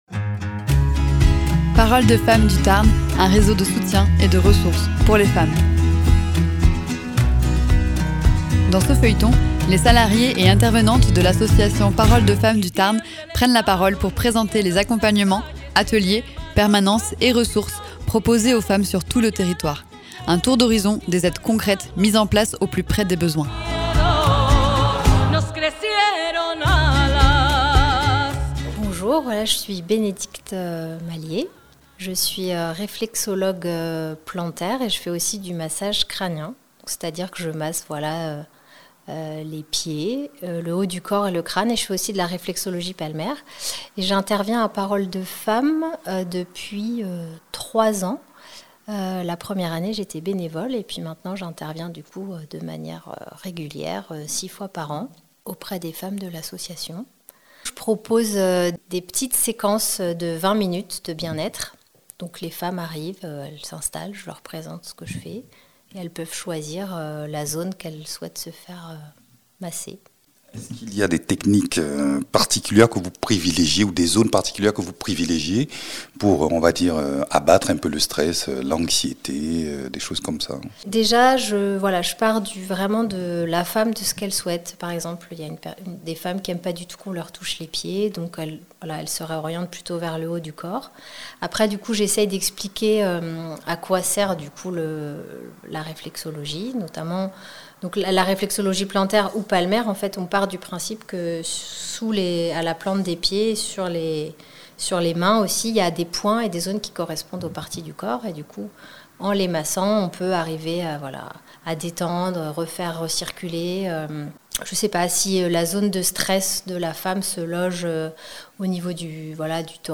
réflexologue.